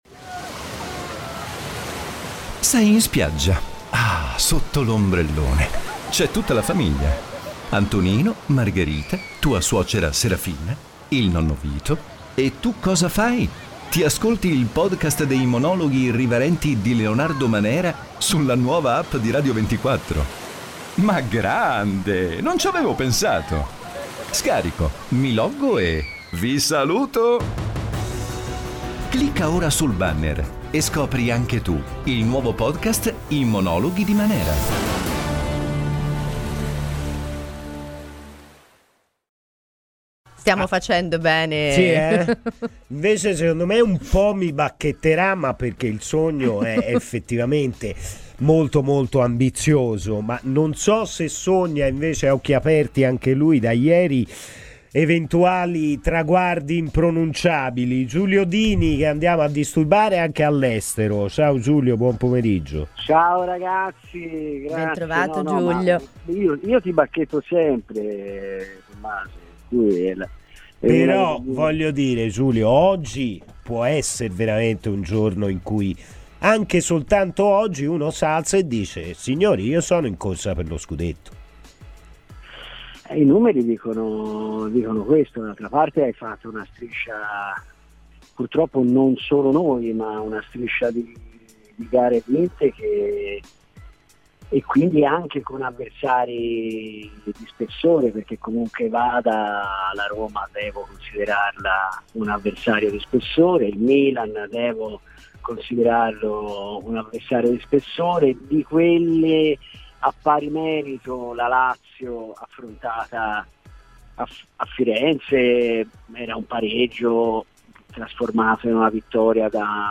ha parlato a 'Palla al centro', in diretta su Radio FirenzeViola.